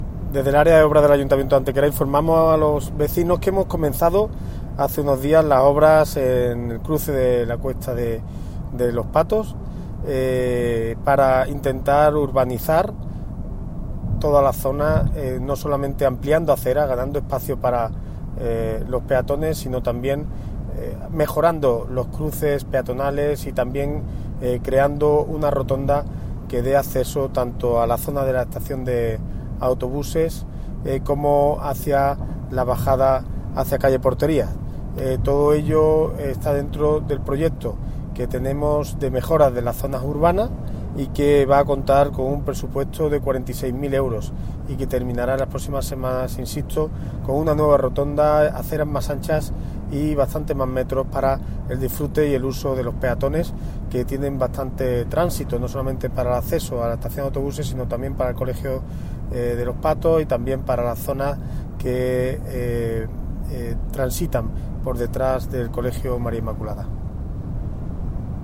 El concejal delegado de Obras del Ayuntamiento de Antequera, José Ramón Carmona, informa del inicio de las obras de mejora del entorno del conocido como cruce de Los Patos, en la confluencia entre el paseo María Cristina, la calle Porterías, la cuesta Miraflores o el paseo García del Olmo.
Cortes de voz